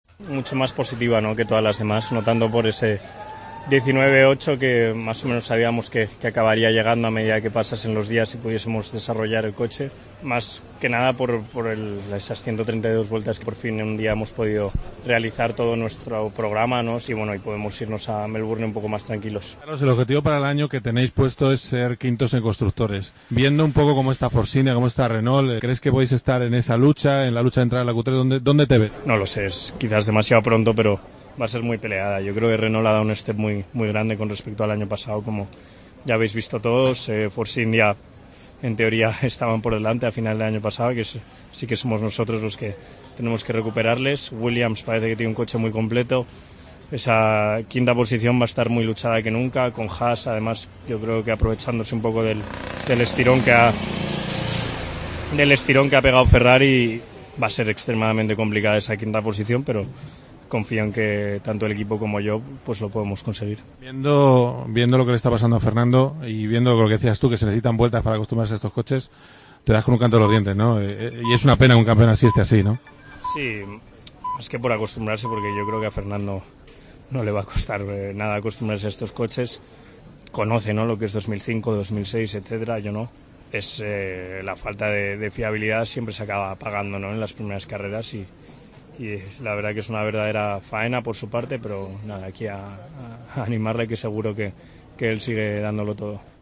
habló con el piloto de Toro Rosso durante la última jornada de test de pretemporada en Montmeló.